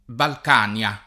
Balcania [ balk # n L a ] top. f.